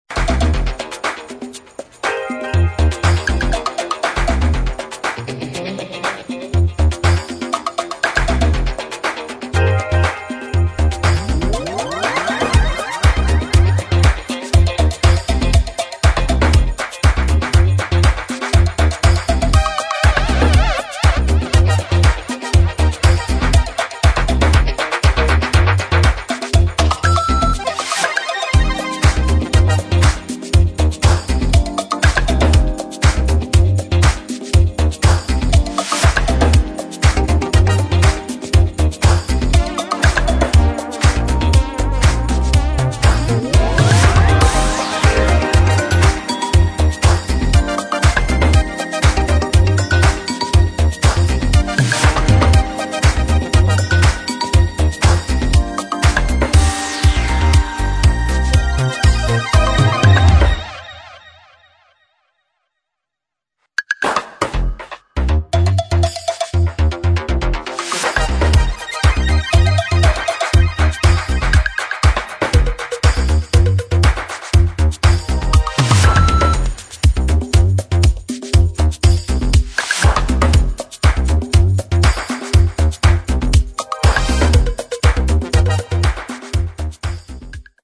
[ DISCO ]
DJ Friendly Instrumental